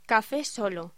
Locución: Café solo